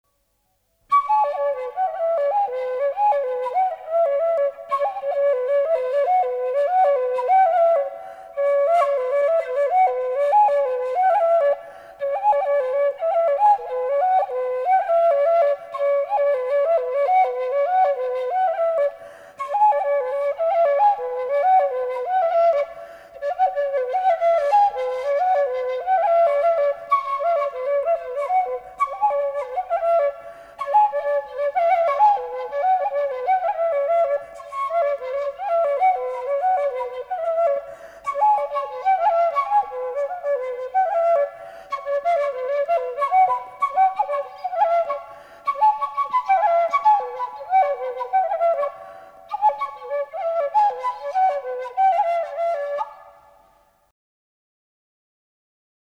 Голоса уходящего века (Курское село Илёк) Матрёнка (дудка, инструментальный наигрыш)
11_Наигрыш.mp3